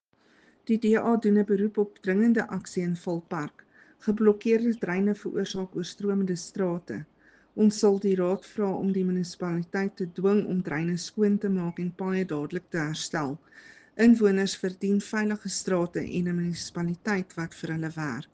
Afrikaans soundbites by Cllr Ruanda Meyer and